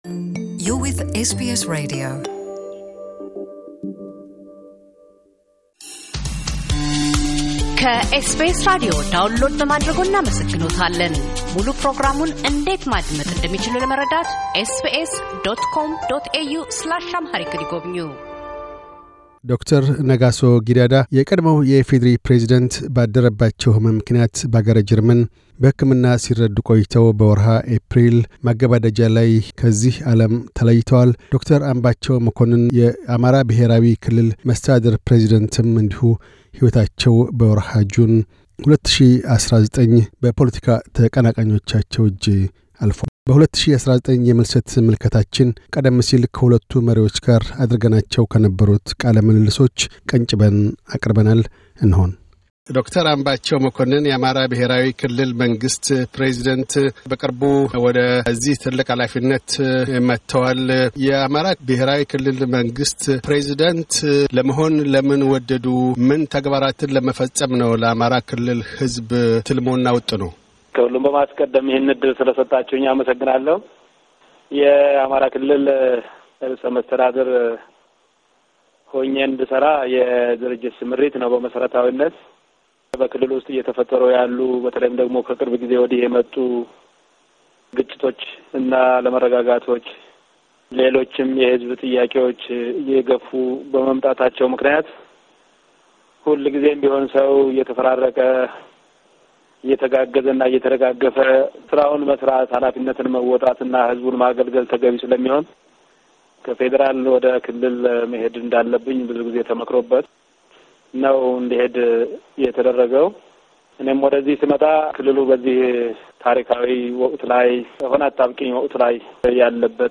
ዶ/ር ነጋሶ ጊዳዳ፤ የቀድሞው የኢፌዴሪ ፕሬዚደንት ባደረባቸው ሕመም ምክንያት በአገረ ጀርመን በሕክምና ሲረዱ ቆይተው በወርሃ ኤፕሪል መገባደጃ 2019 ላይ ከዚህ ዓለም በሞት ተለይተዋል። ዶ/ር አምባቸው መኮንን፤ የአማራ ብሔራዊ ክልል ርዕሰ መስተዳድርም በፖለቲካ ተቃናቃኞቻቸው እጅ ሕይወታቸው በወርኃ ጁን 2019 አልፏል። በ2019 የምልሰት ምልከታችን ቀደም ሲል ከሁለቱ መሪዎች ጋር አድርገናቸው ከነበሩት ቃለ ምልልሶች ቀንጭበን አቅርበናል።